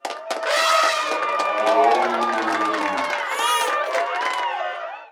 croud.wav